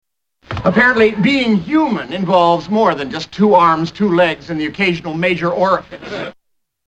Category: Television   Right: Personal
Tags: 3rd Rock from the Sun TV sitcom Dick Solomon John Lithgow Dick Solomon clips